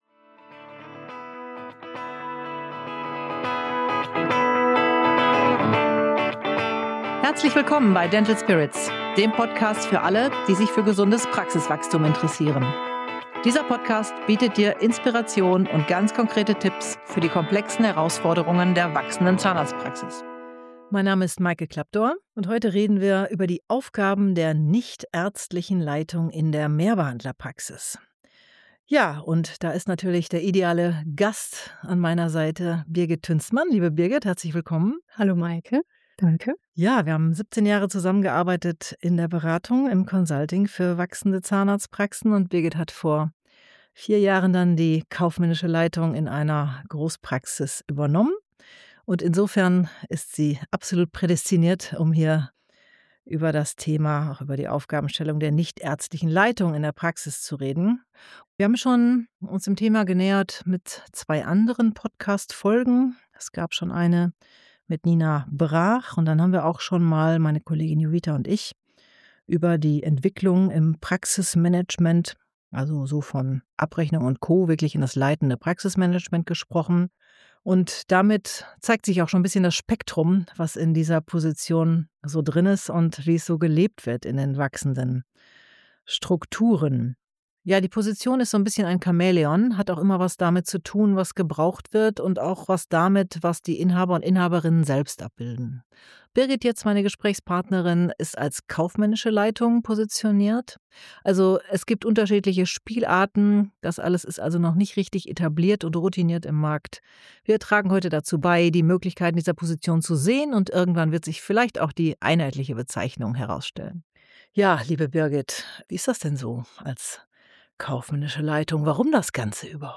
Ein anregender Austausch zur Frage, wie große Praxen professionell – im Sinne von: gleichermaßen kulturbewusst wie wirtschaftlich erfolgreich – geführt werden können.